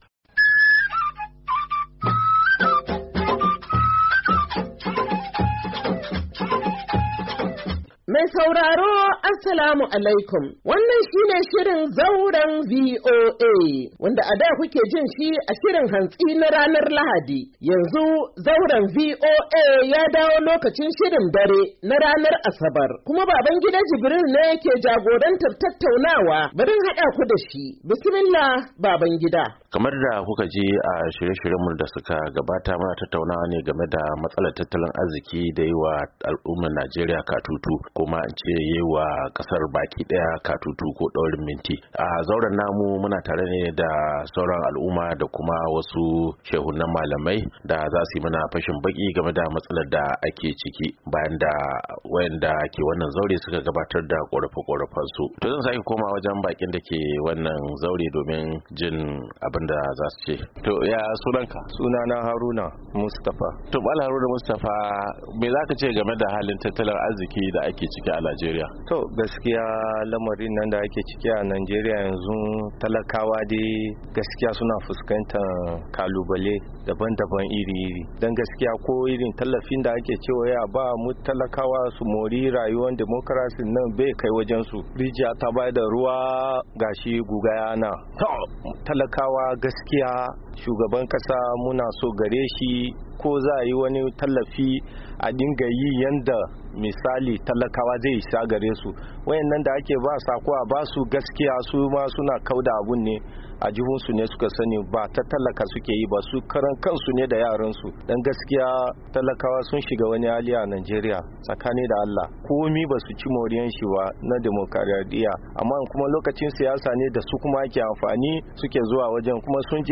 Shirin Zauran VOA na wannan makon, ya tattauna da wasu 'yan Najeriya da suka nuna rashin gamsuwarsu da yadda kayan tallafi da gwamnatin tarayya take aikawa ba ya kai wa gare su. Sun yi kira da cewa gwamnati ta dauki kwararan mataki na kawo karshen mawuyacin hali da 'yan kasa ke ciki a sakamako cire tallafin man fetur da Shugaban kasa Bola Ahmed Tinubu ya cire a shekarar da ta gabata.